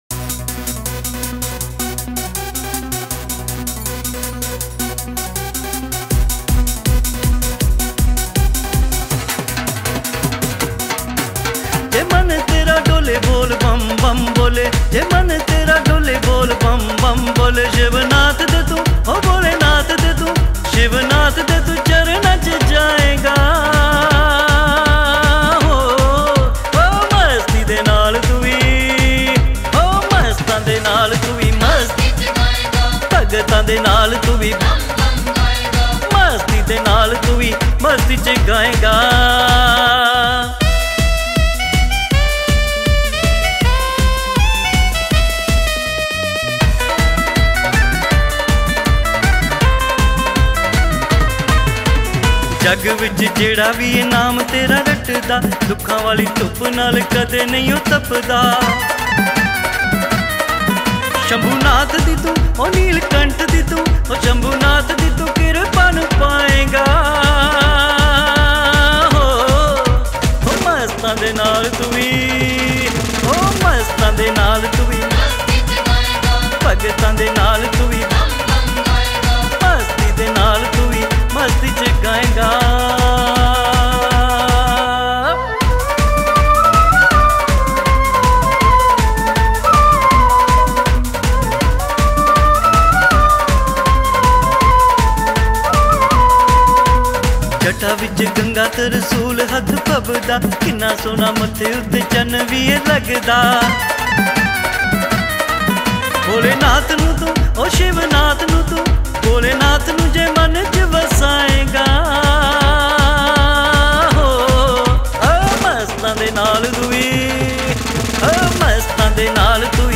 Shiv Bhajan